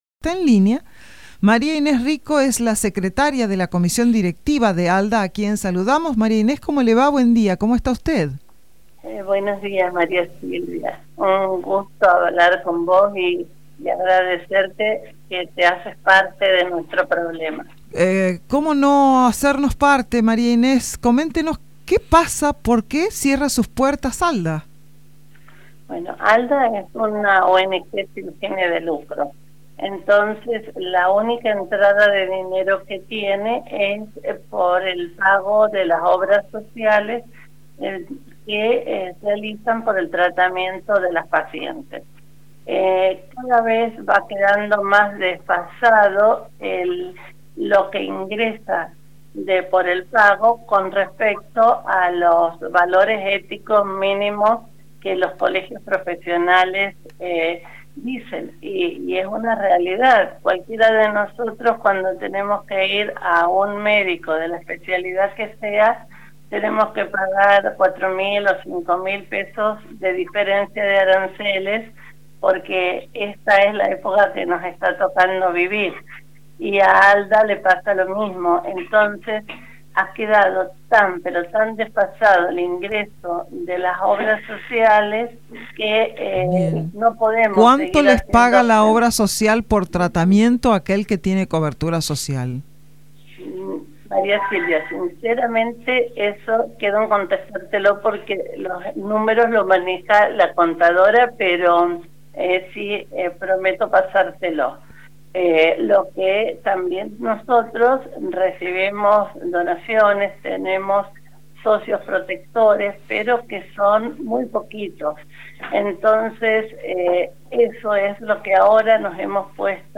En una entrevista con LV5 Sarmiento, expresó su preocupación y la necesidad de evitar este cierre.